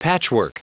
Prononciation du mot patchwork en anglais (fichier audio)
Prononciation du mot : patchwork